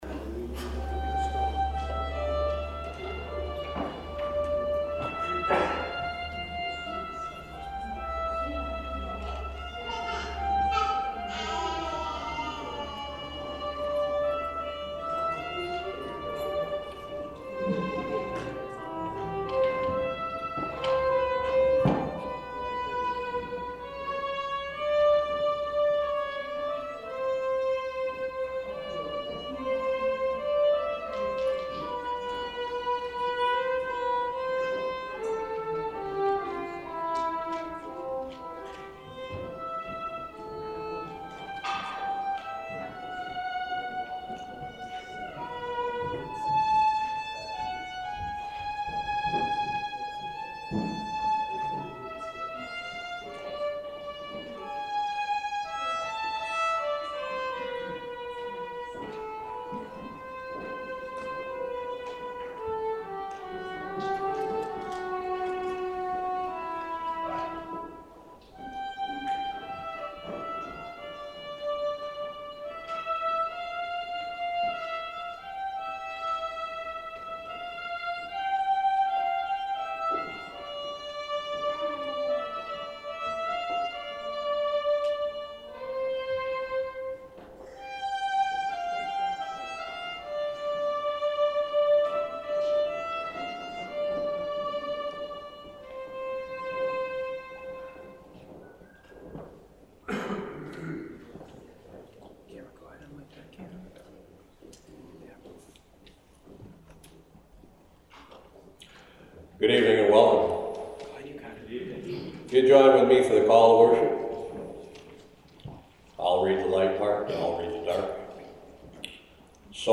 April 14th, 2017 Good Friday Service Podcast
WBC-4-14-17-goodfriday.mp3